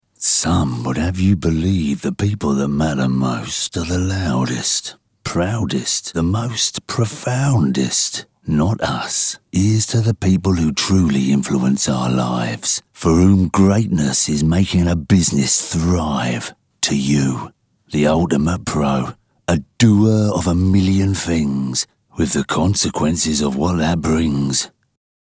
Male
Adult (30-50), Older Sound (50+)
Radio Commercials
Husky, Soft London Accent
0825Husky_Ldn.mp3